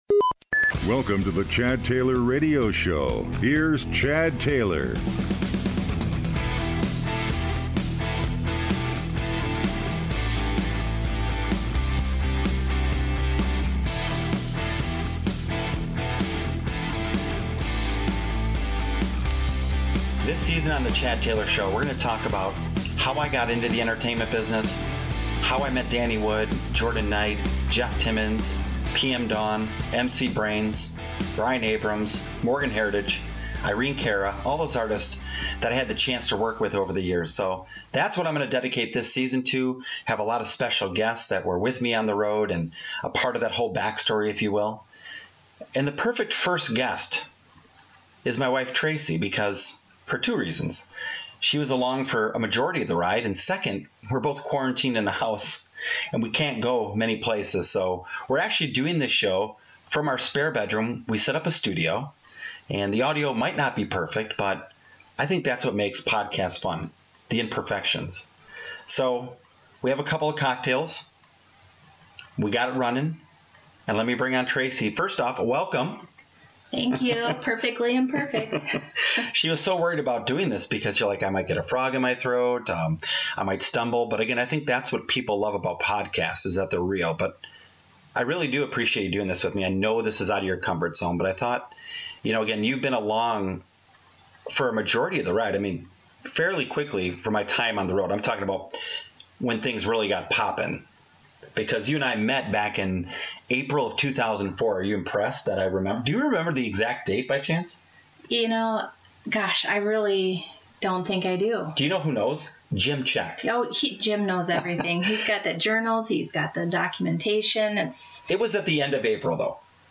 Headliner Embed Embed code See more options Share Facebook X Subscribe This season is all about revisiting my past with special guests along the way. Since the coronavirus has us all self distancing and working remotely, this episode was recorded in my spare bedroom, so it's not studio quality.